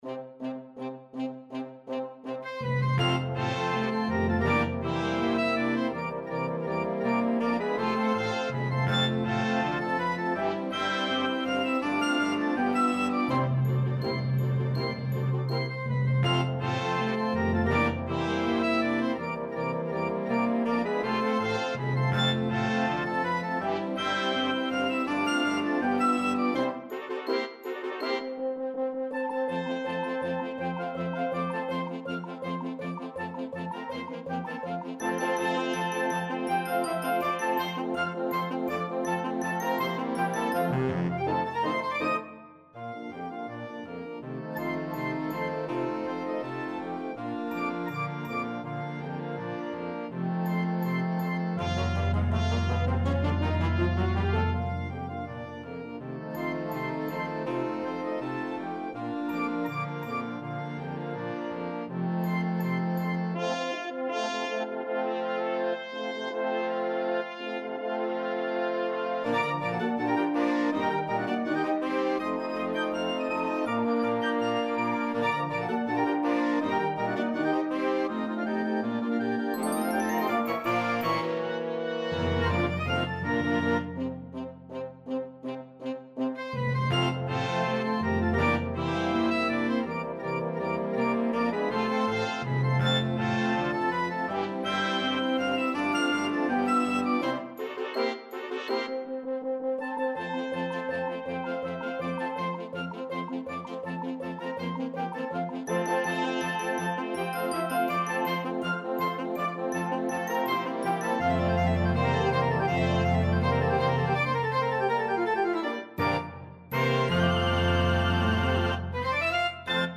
Schnellpolka